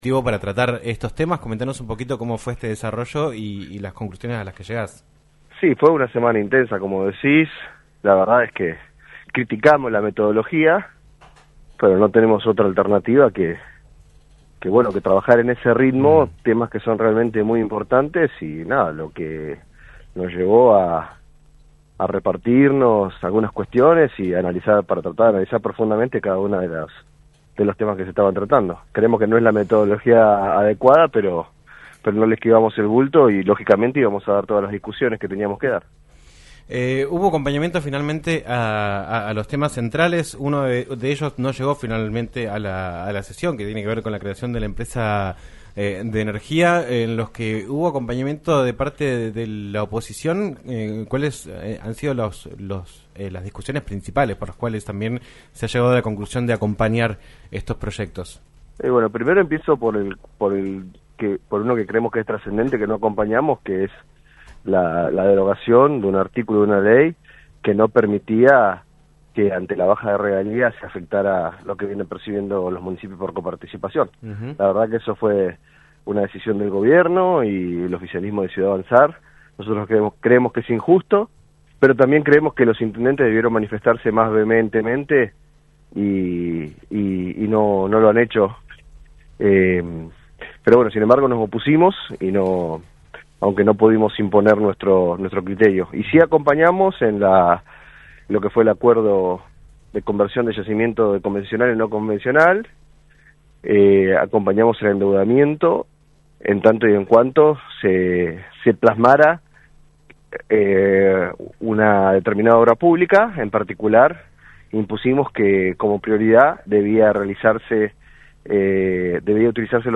Juan Pais, diputado provincial por el bloque Arriba Chubut, habló en "Un Millón de Guanacos" por LaCienPuntoUno sobre la sesión de ayer en la Legislatura donde se aprobó el endeudamiento por 650 millones de dólares para pagar vencimientos de deuda y realizar obra pública.